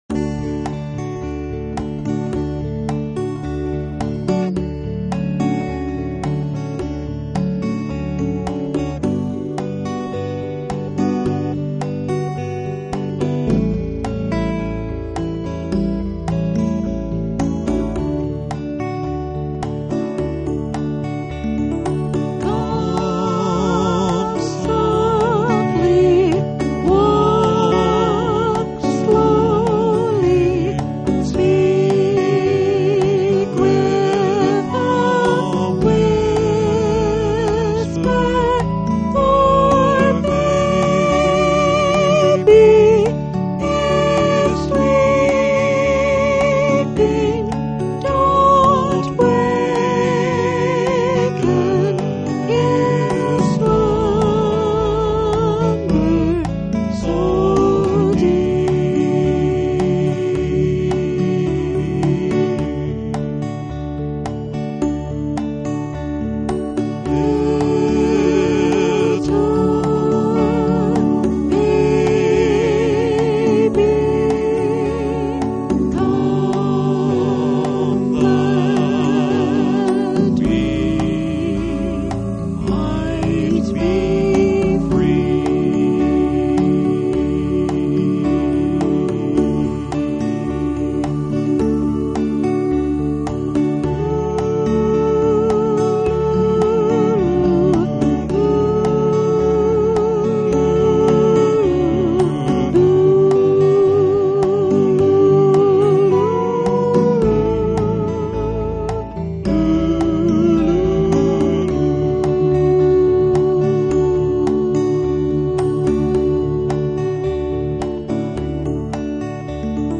A lullaby written especially for Christmas Eve services